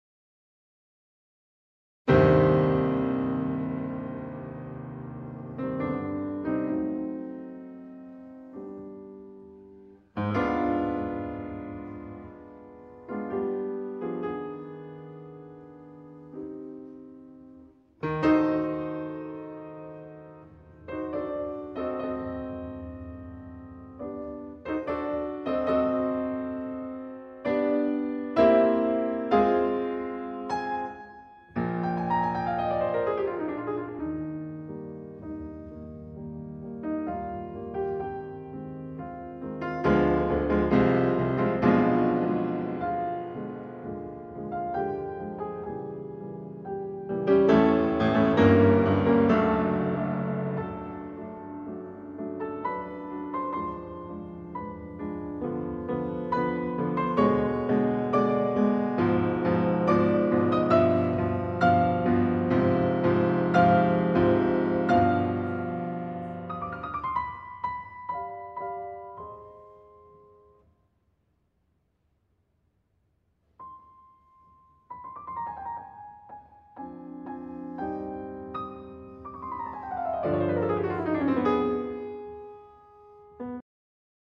"P" stands for piano (soft); "cres" tells the player to get louder; and "FF" (fortissimo) means play very loud.
piano The entire slow (Grave) introduction to the sonata (mm. 1-10) Download this file